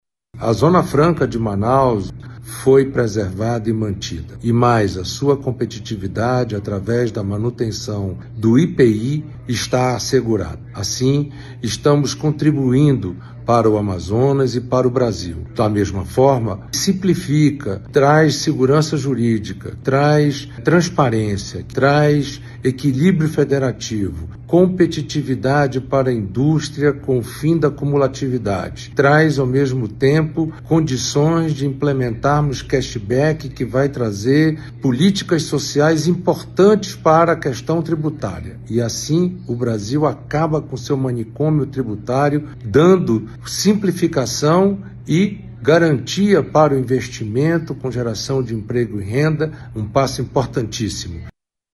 O senador Eduardo Braga (MDB) disse que a reforma beneficia o Amazonas e o Brasil.